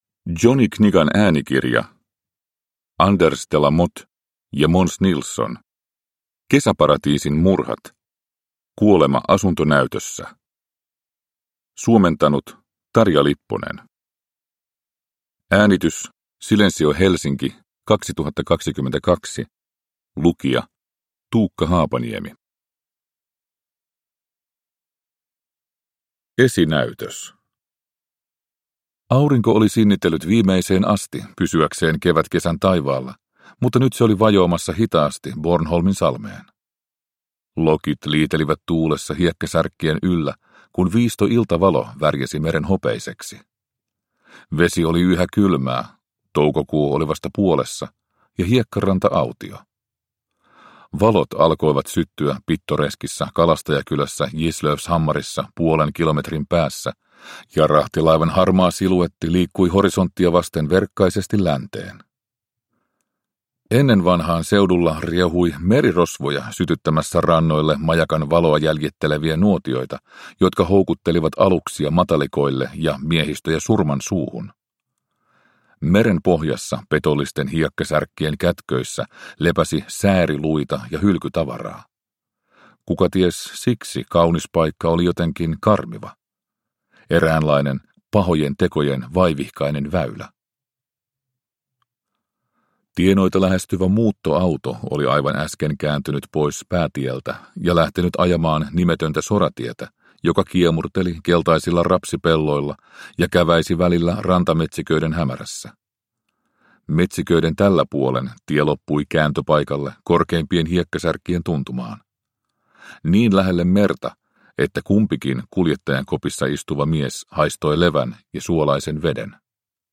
Kuolema asuntonäytössä – Ljudbok – Laddas ner